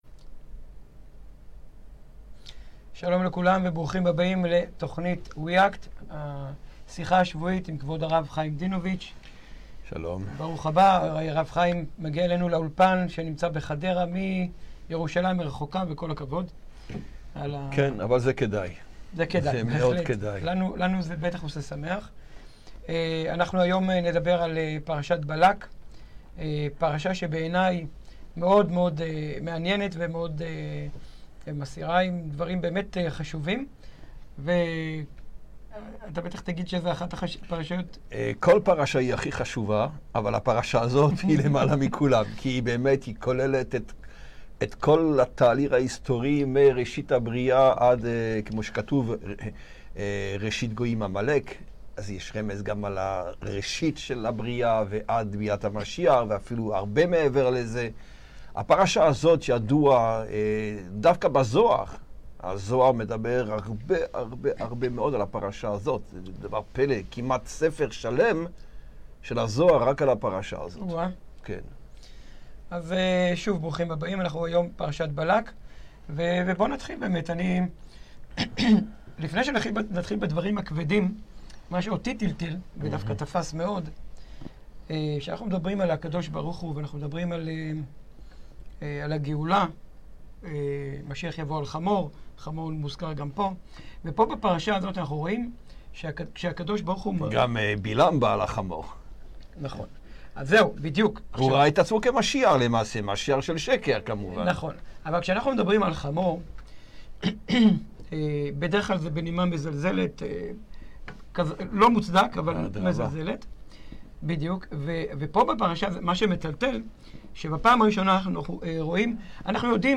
השיחה השבועית